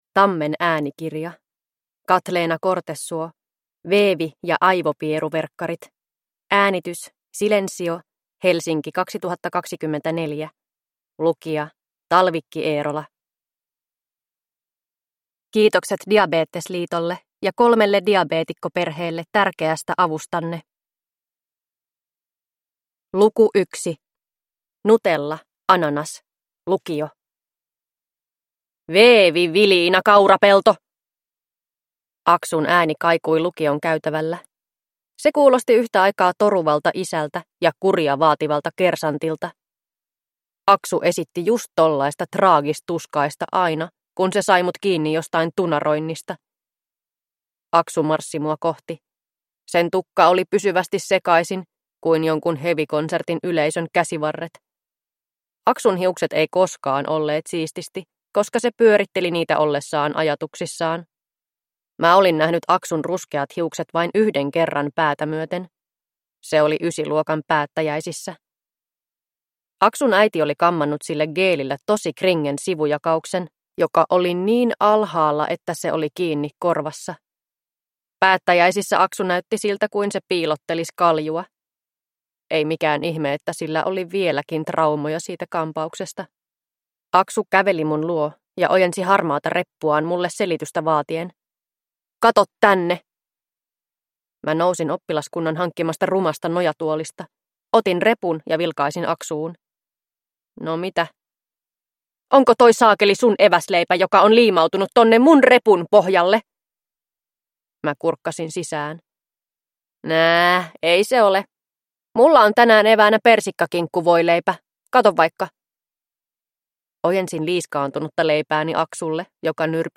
Veevi ja aivopieruverkkarit – Ljudbok